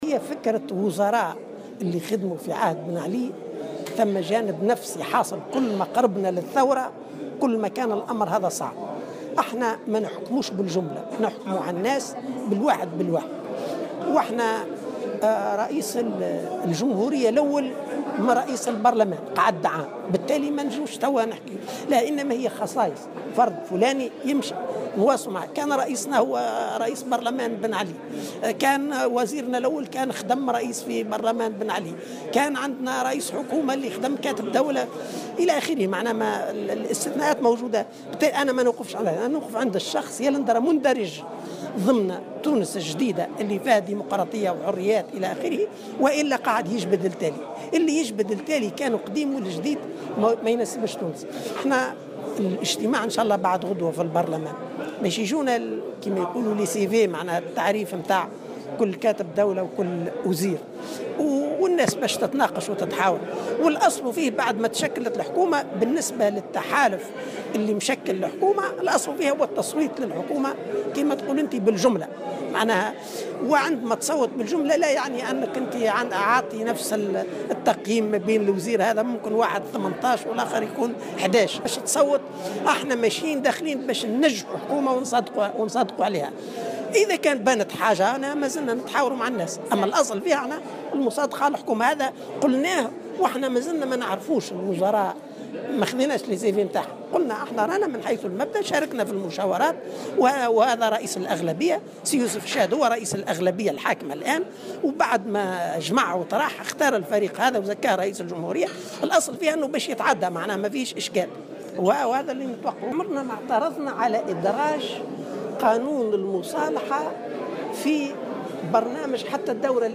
و فيما يتعلّق بقرار الحركة بخصوص المصادقة على هذه التركيبة من عدمه، أوضح العريض في تصريح لمراسل "الجوهرة أف أم" اليوم على هامش حضوره المؤتمر الثالث لحزب التكتل، أن الأصل هو أن يصوّت التحالف المشكّل للحكومة بالجملة على الأعضاء الجدد.